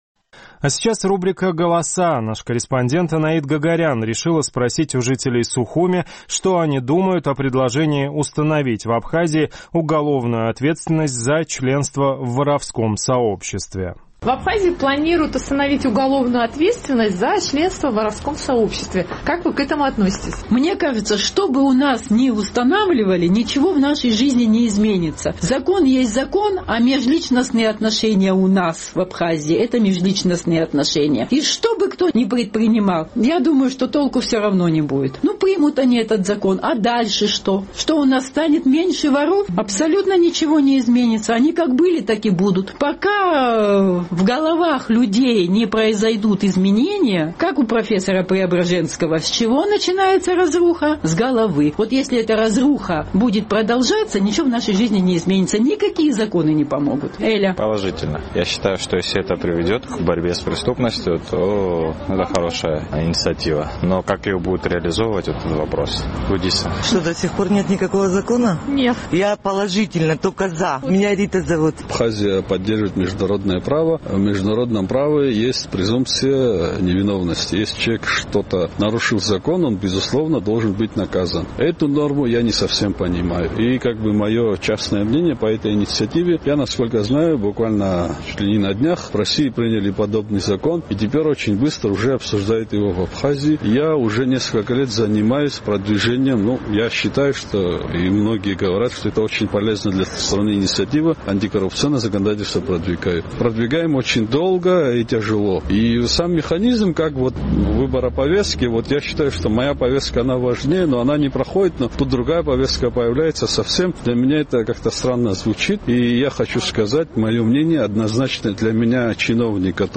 В Абхазии планируют установить уголовную ответственность за членство в воровском сообществе. Наш корреспондент узнавала, как к этому относятся жители абхазской столицы.